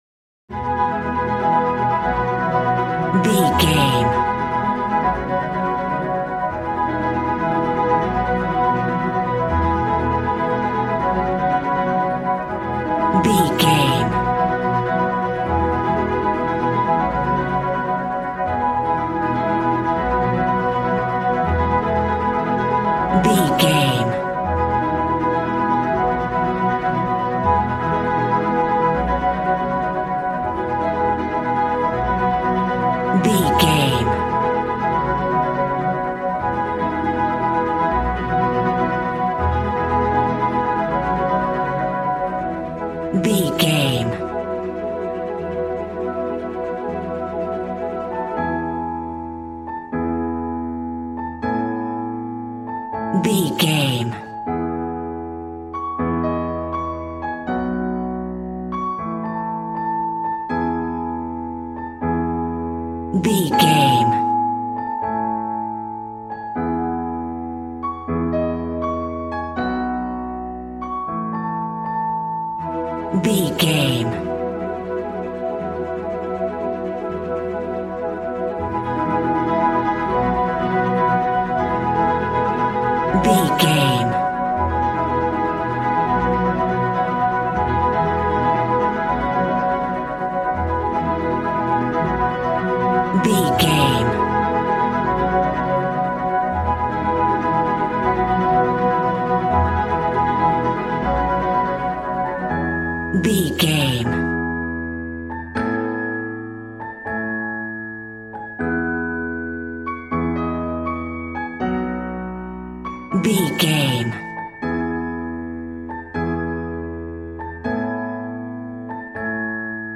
Regal and romantic, a classy piece of classical music.
Ionian/Major
regal
strings
violin
brass